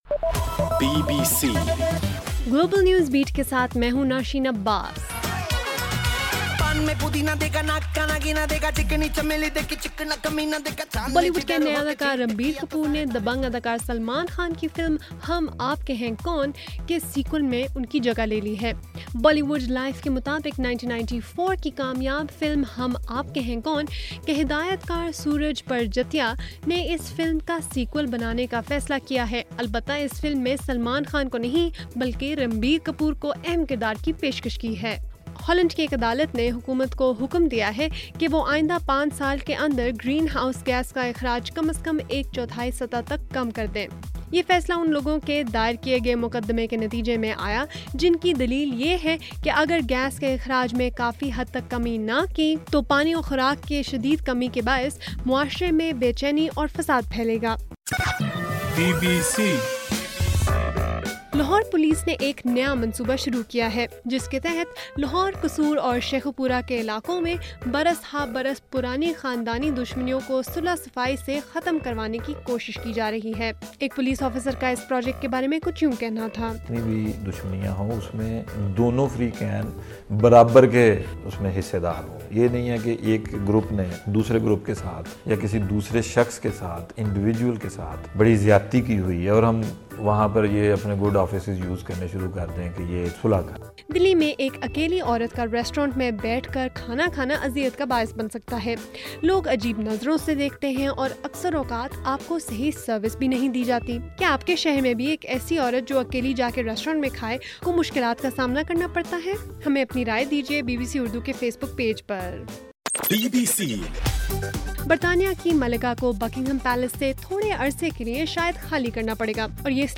جون 24: رات 9 بجے کا گلوبل نیوز بیٹ بُلیٹن